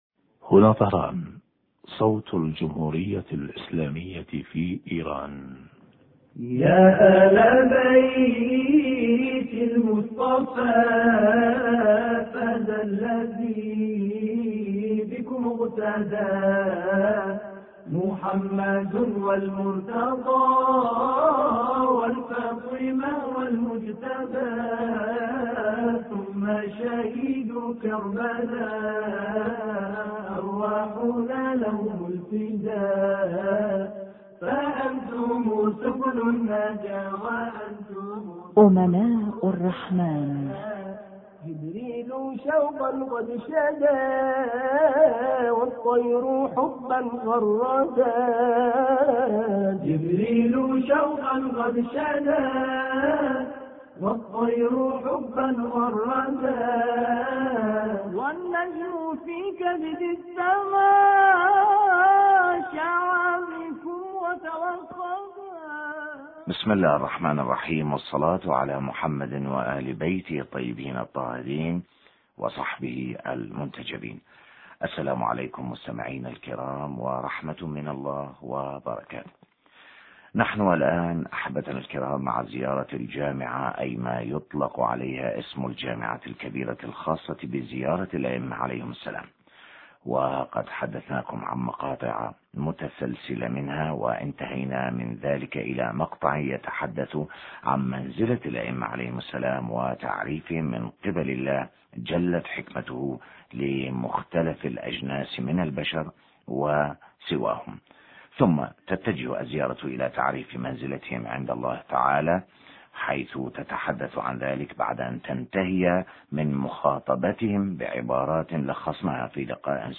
شرح فقرة: وصدق مقاعدكم وبيان قربهم من الله عزوجل حوار